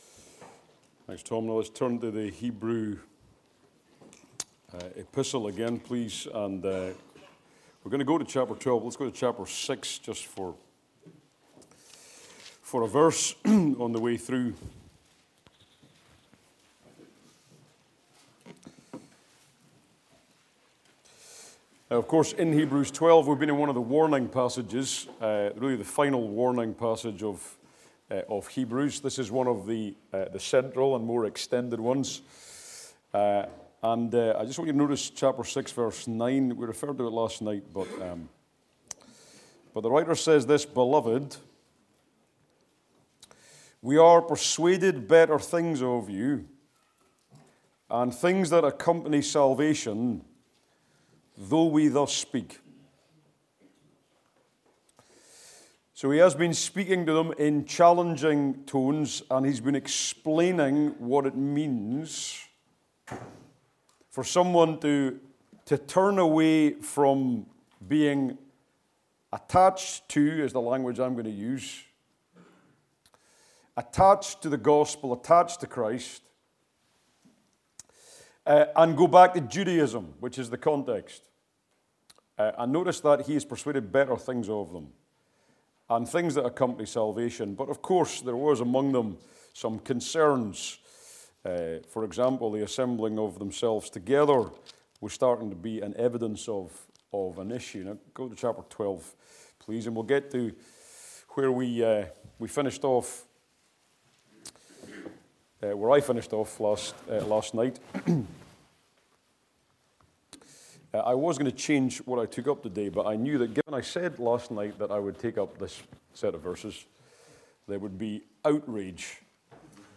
2026 Easter Conference